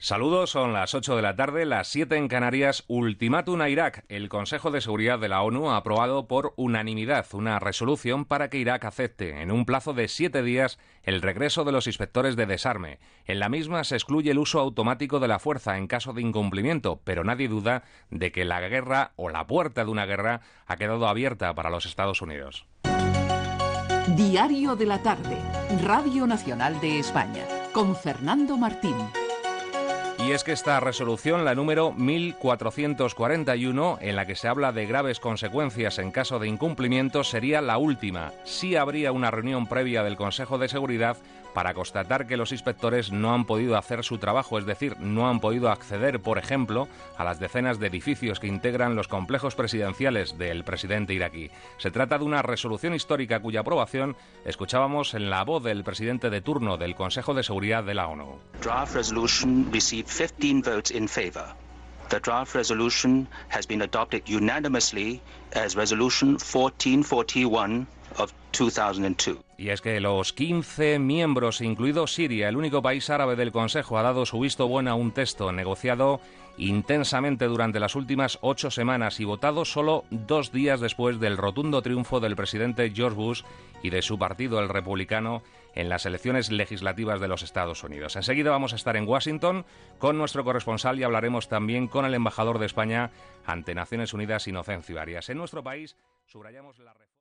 Hora, titular sobre Iraq, careta del programa i informació sobre l'ultimàtum de Nacions Unides a Iraq amb la resolució 1441, perquè deixi accedir als inspectors de l'ONU
Informatiu